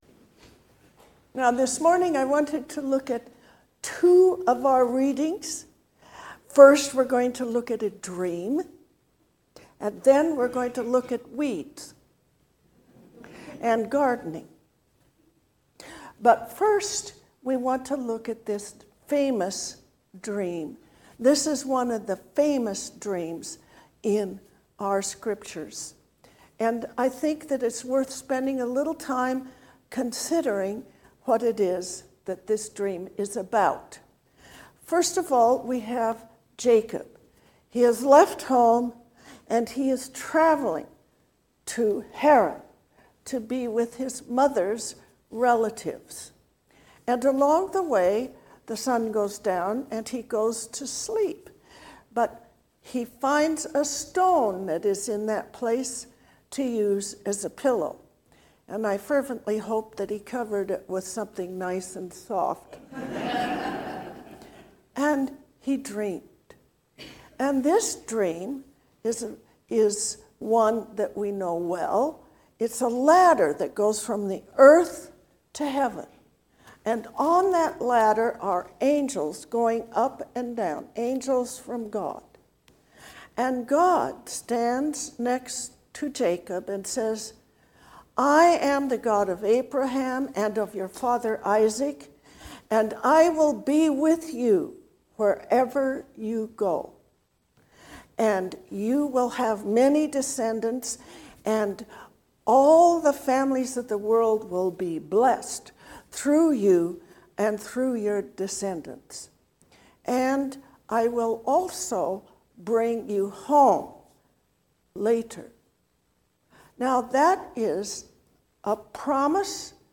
A Sermon for the Eighth Sunday after Pentecost